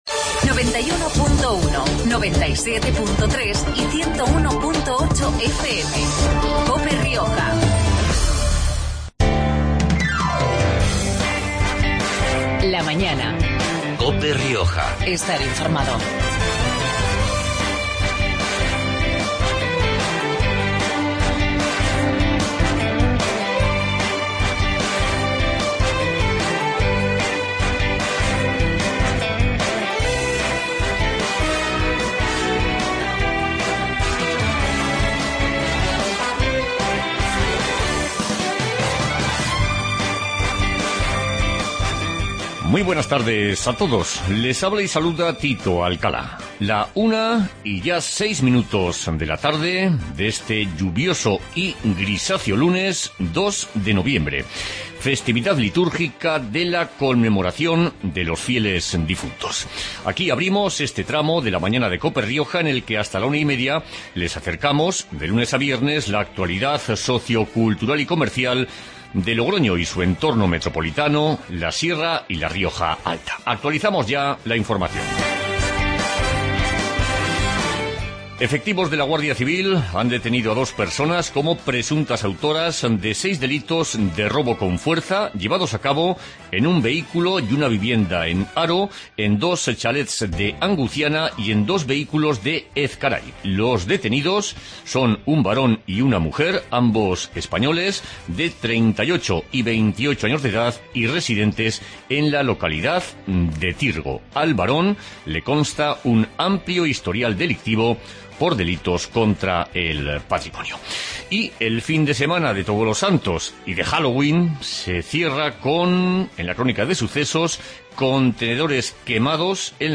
Magazine de actualidad riojana